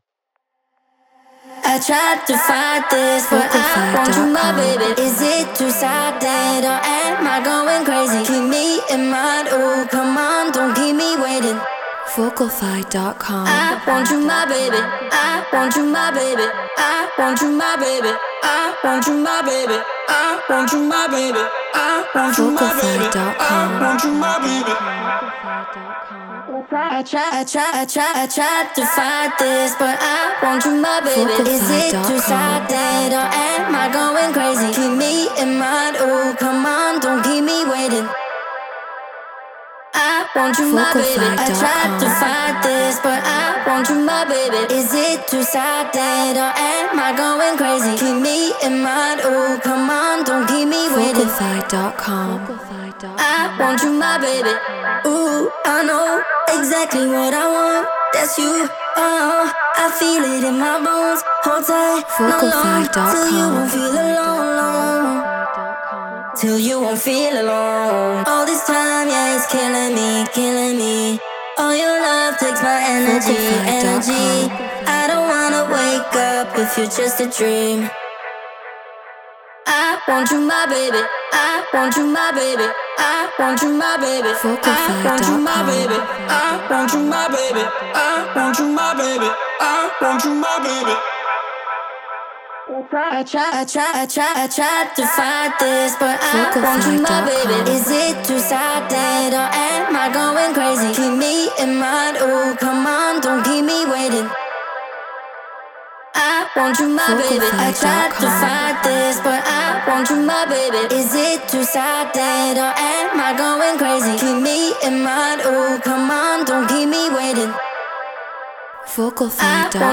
UKG 145 BPM Amin
Neumann TLM 103
Treated Room